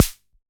RDM_TapeA_SR88-Snr.wav